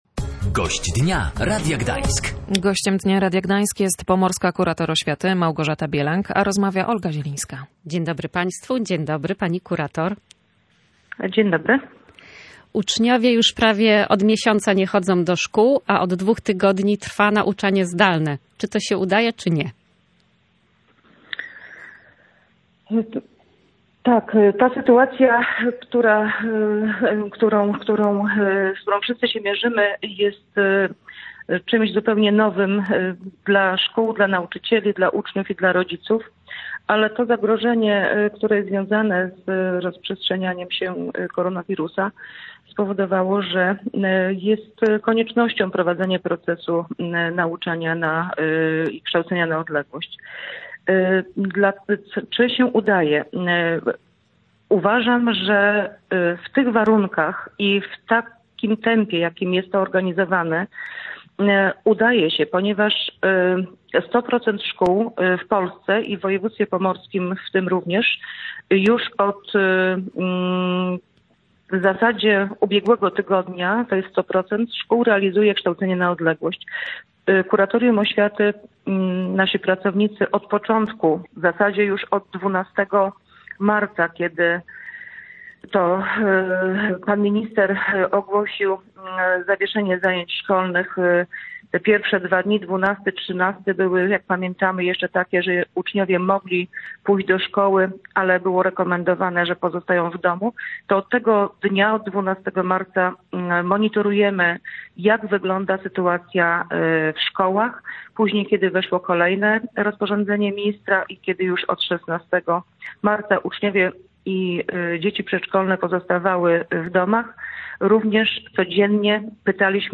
Gościem Dnia Radia Gdańsk była Małgorzata Bielang, Pomorska kurator oświaty.